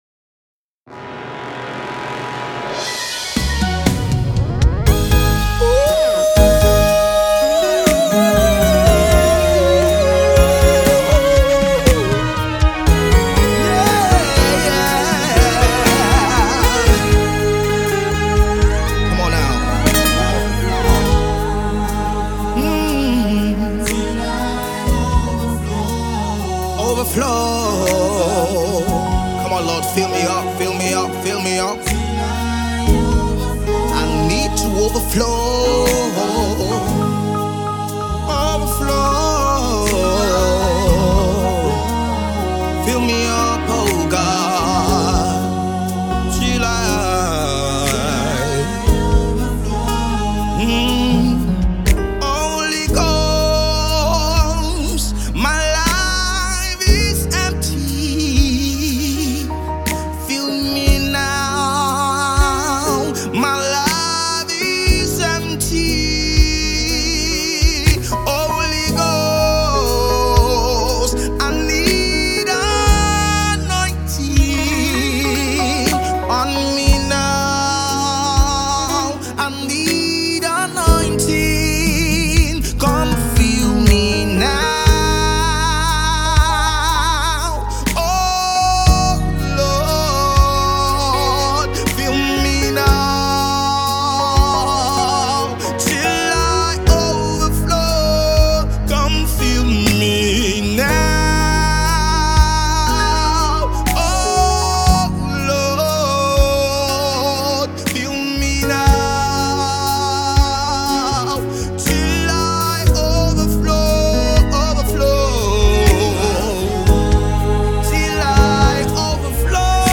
Nigerian gospel music collective